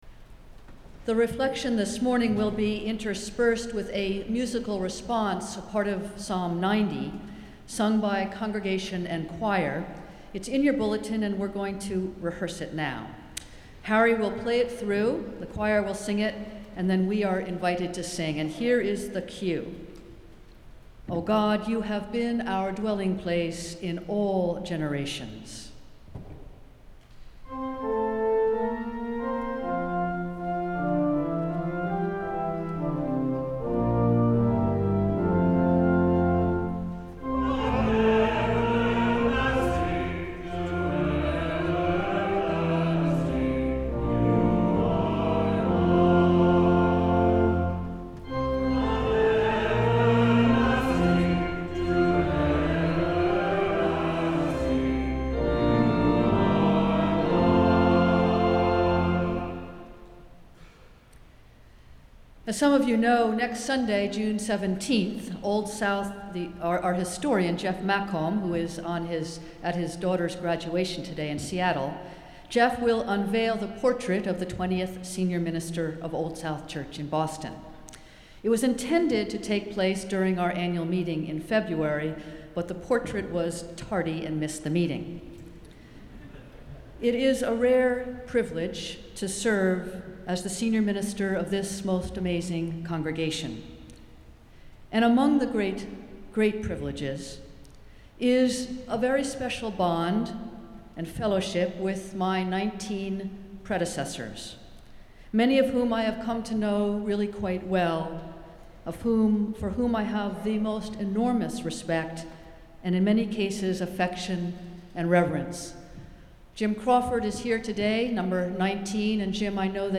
Festival Worship - Second Sunday after Pentecost